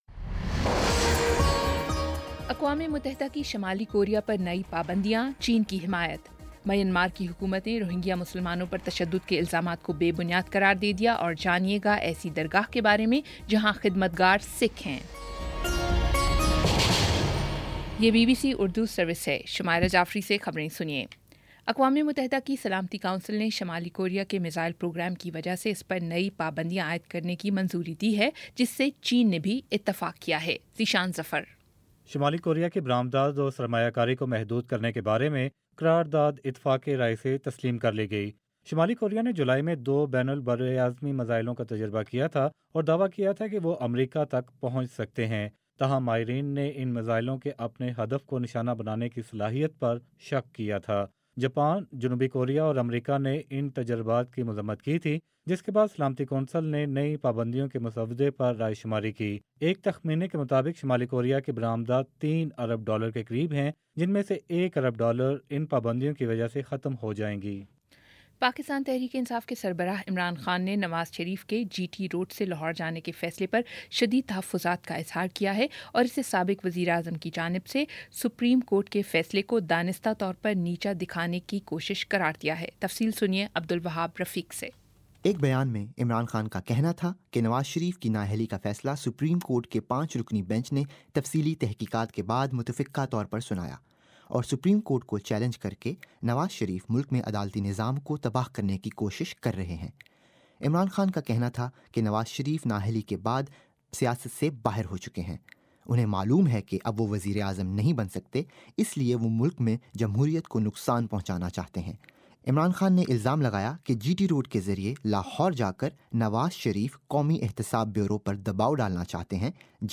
اگست 06 : شام چھ بجے کا نیوز بُلیٹن